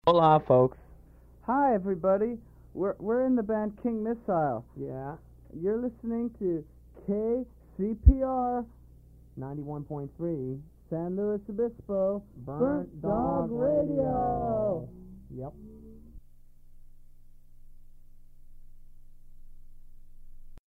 King Missile [station identification]
Form of original Audiocassette